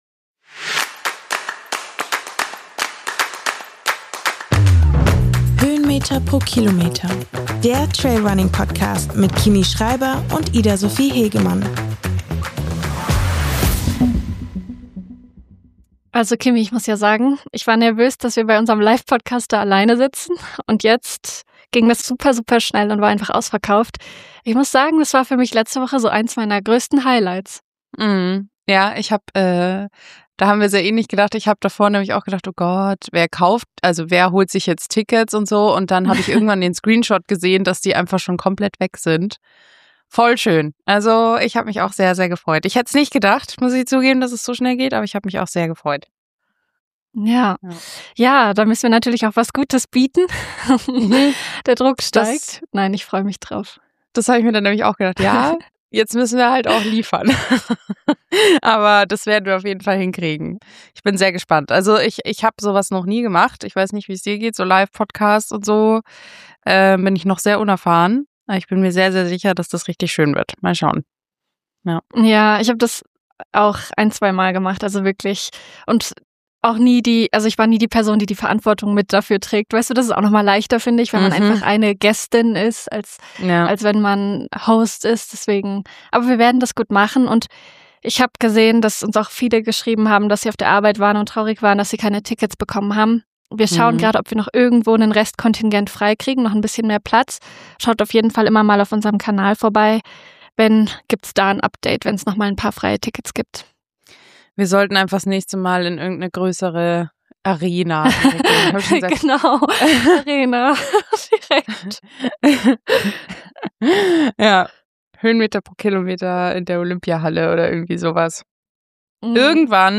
Diese Woche senden wir halb aus Innsbruck und halb von Mallorca.